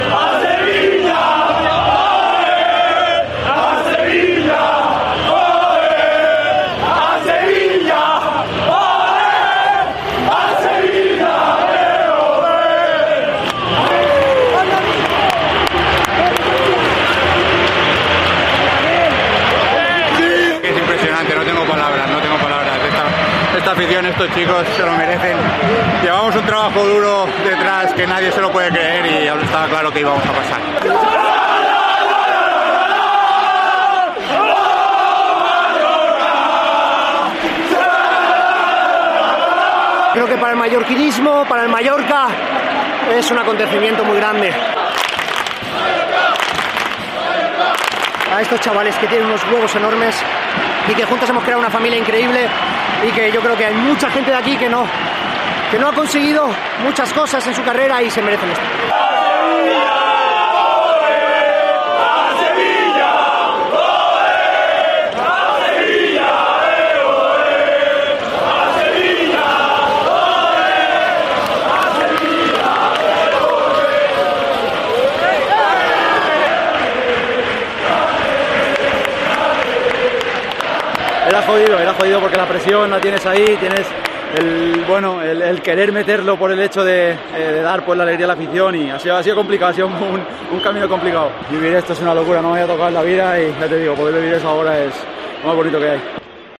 "A Sevilla oé,oé" gritaban los futbolistas bermellones, o "es una L" repetían todavía en el césped recordando a Merino su celebración en Son Moix
"A Sevilla, oé", los sonidos del finalista de Copa del Rey, el RCD Mallorca